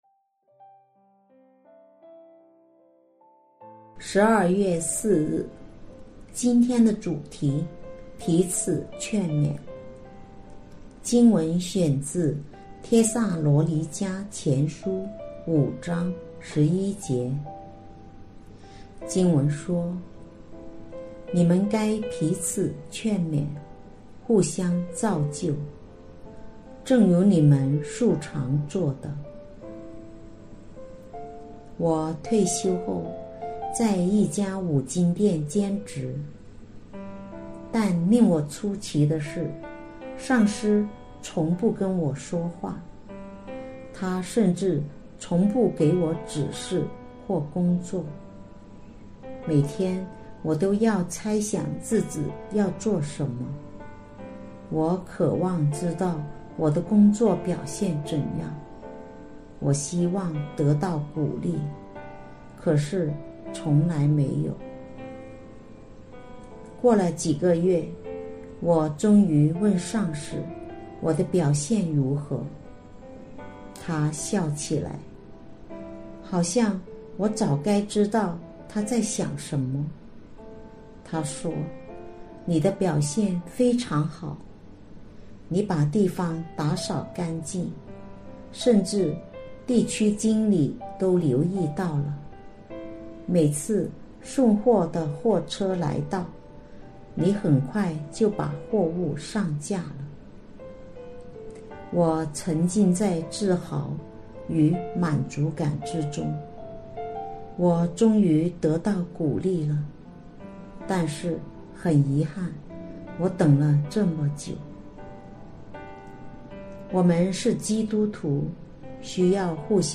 錄音員